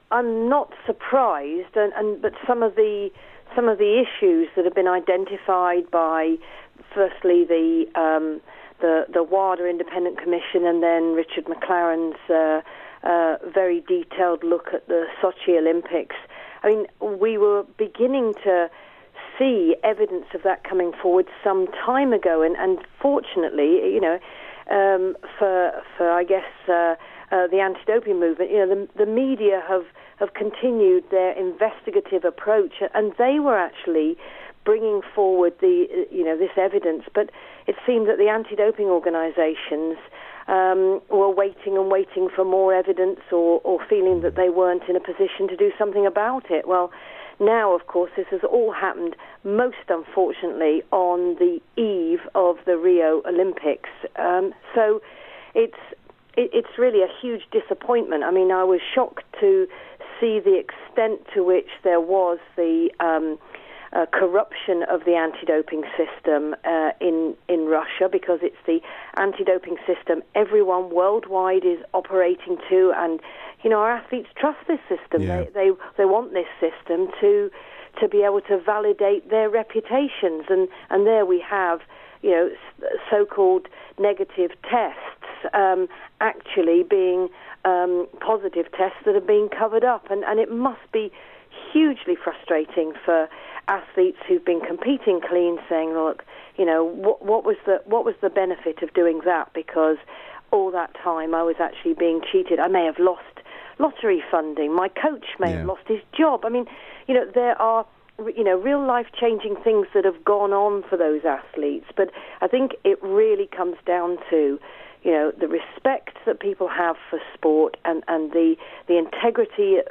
Sports Ethics & Anti-Doping Adviser talks us through the latest goings on after recent announcements by WADA and the IOC